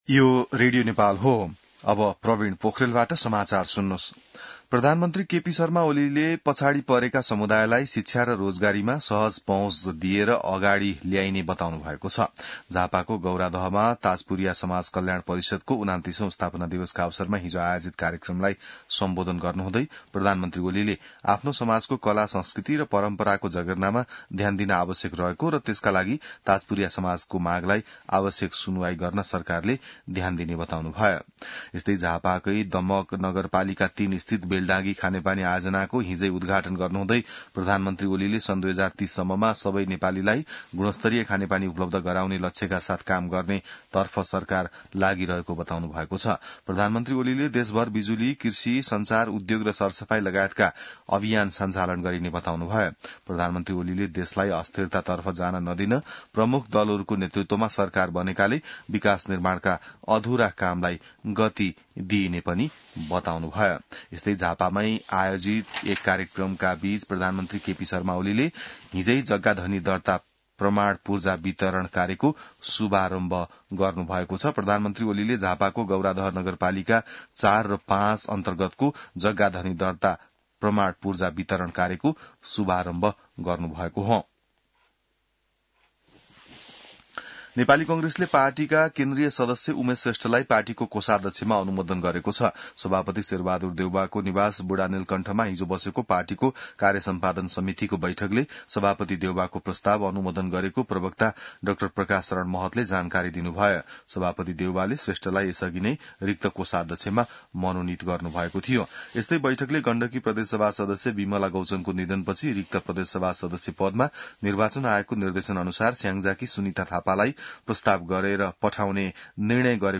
An online outlet of Nepal's national radio broadcaster
बिहान ६ बजेको नेपाली समाचार : २६ मंसिर , २०८१